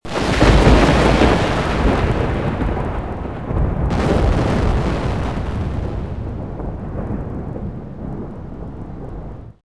TRUENOW THUNDER
Ambient sound effects
Descargar EFECTO DE SONIDO DE AMBIENTE TRUENOW THUNDER - Tono móvil
Truenow_thunder.mp3